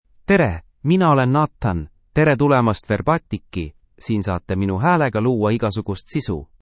NathanMale Estonian AI voice
Nathan is a male AI voice for Estonian (Estonia).
Voice sample
Male